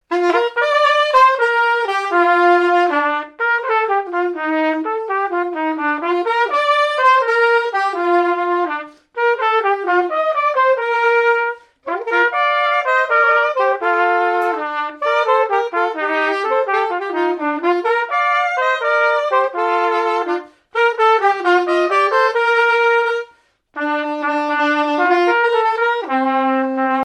circonstance : fiançaille, noce
répertoire de marches de noces
Pièce musicale inédite